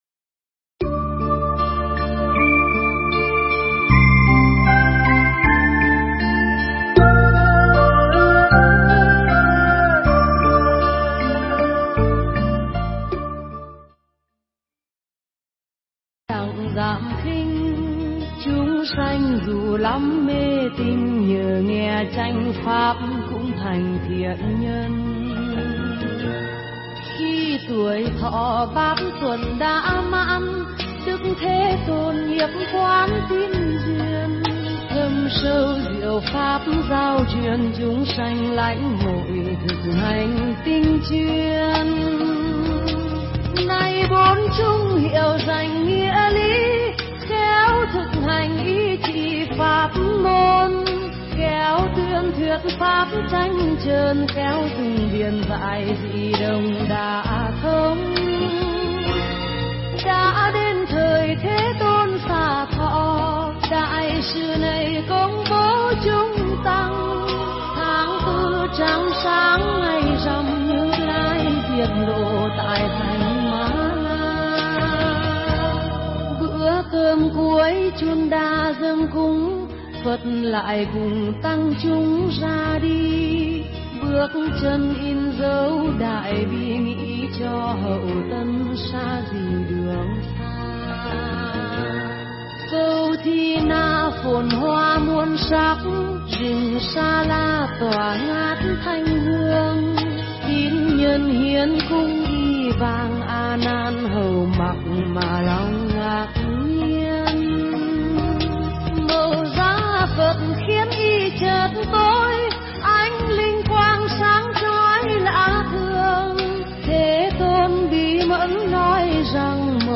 Nghe Mp3 thuyết pháp Trả Lời Câu Hỏi